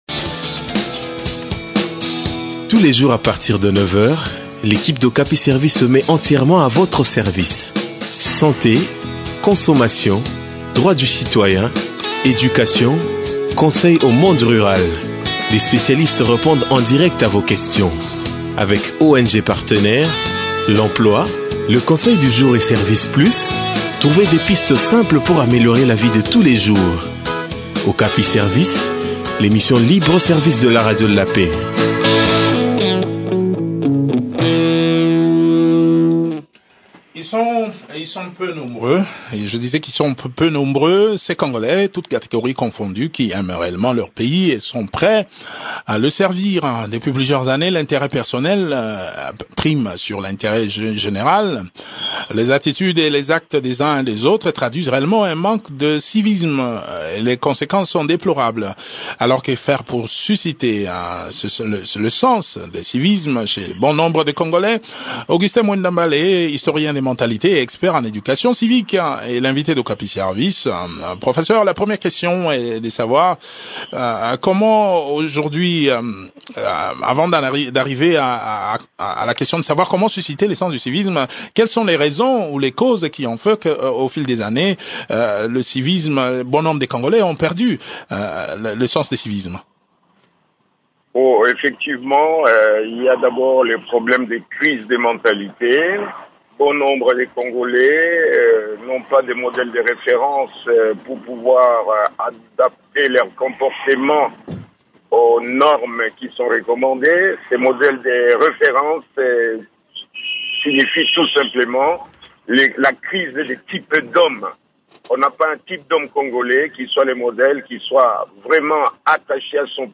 historien des mentalités et expert en éducation civique.